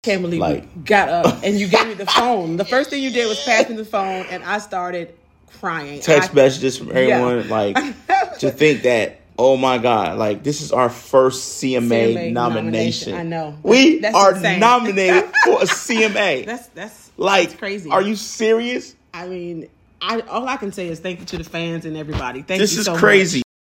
:24 NOTE: quick cutoff at the end